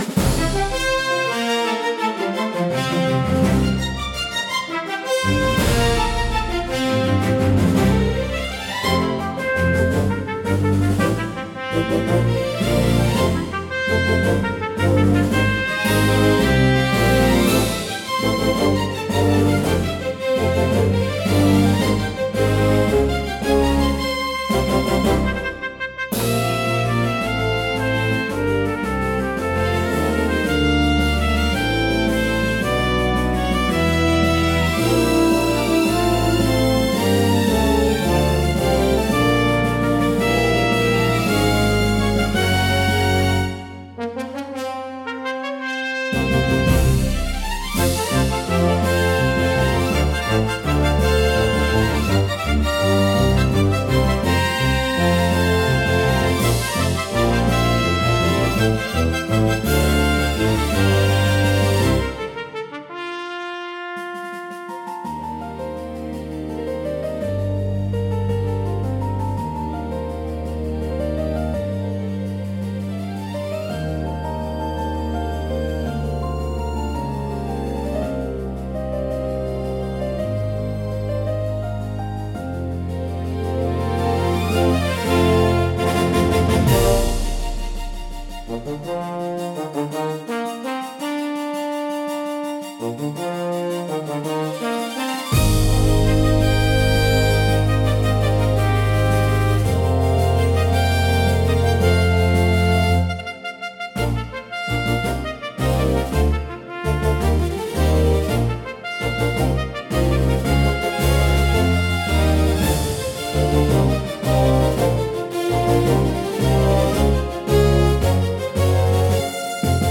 高揚感と喜びを強調し、場の雰囲気を盛り上げる役割を果たします。華やかで勢いのあるジャンルです。